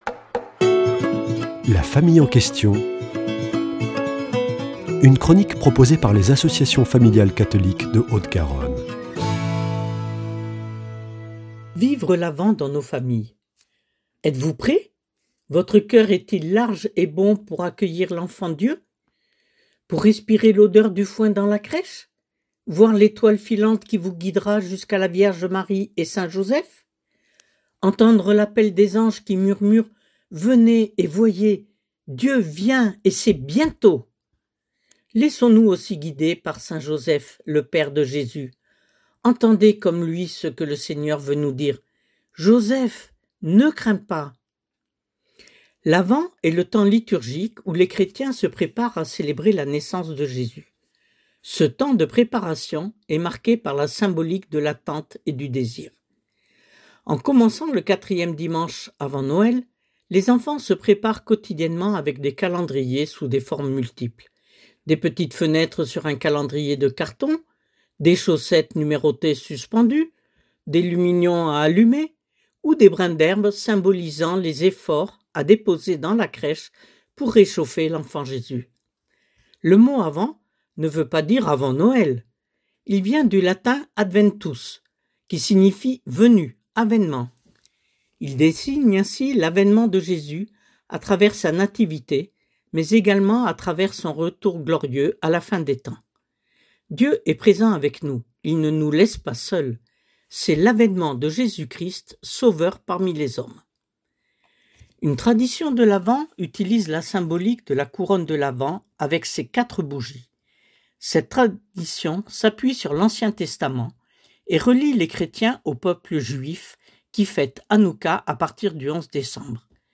mercredi 26 novembre 2025 Chronique La famille en question Durée 3 min